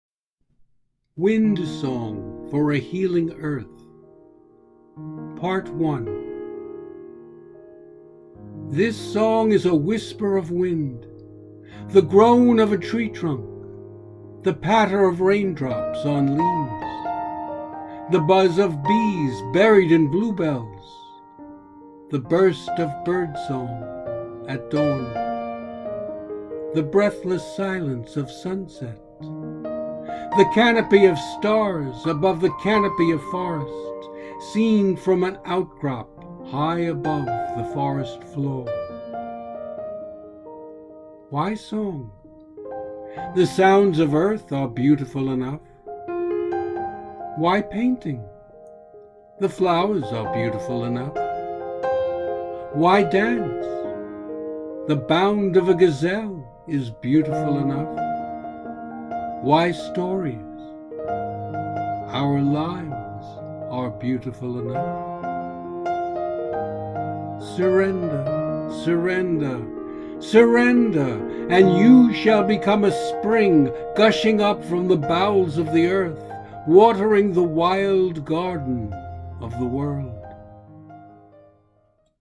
Audio and Video Music:
Music free to use